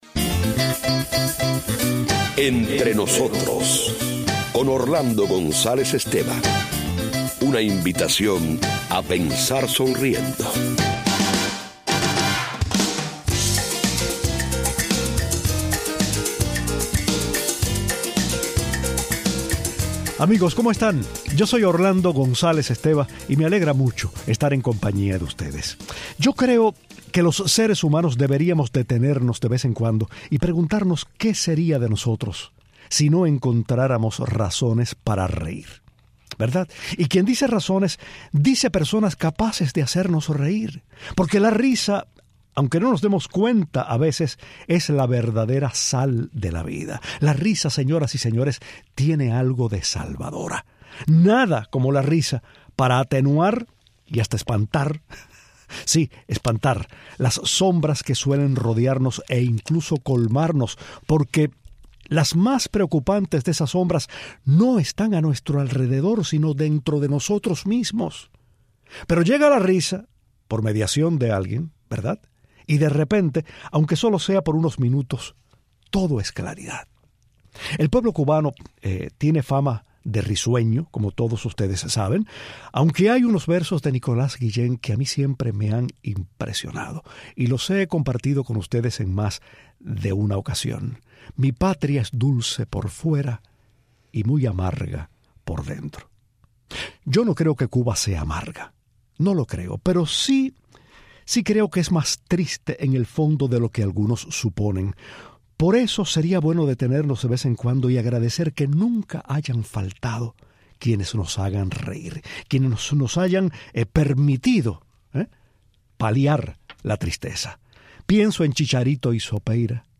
La estupenda actriz, comediante e imitadora puertorriqueña nos habla del arte de hacer reír, de su carrera y de un capítulo estremecedor de su vida.